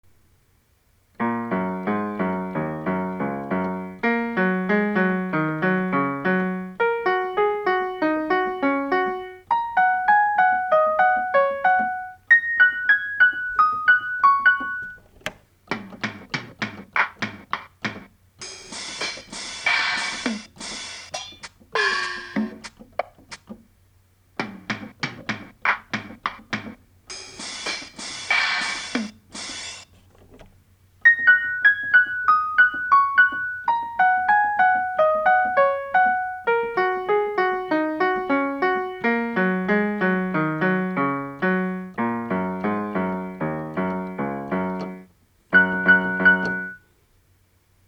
First attemp (there will probably be others) at what it sounds like when all put together.